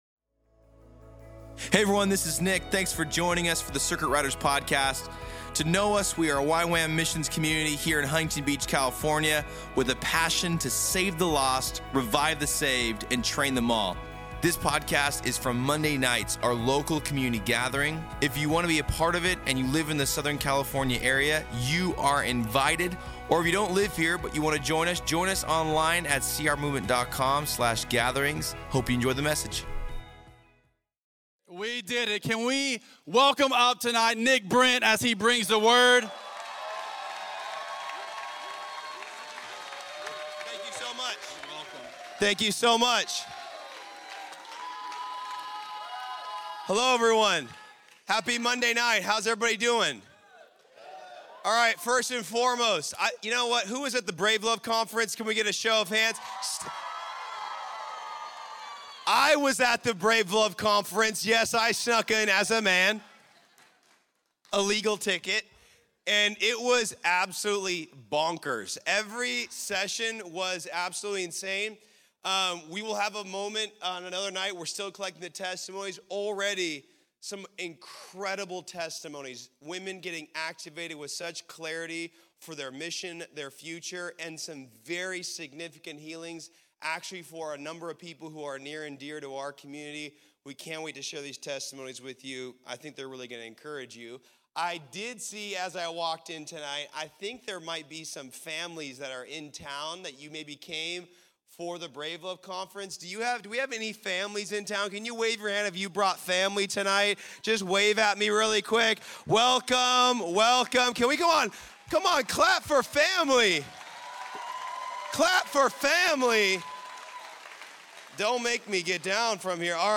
Questions from the sermon are found at the bottom of the description.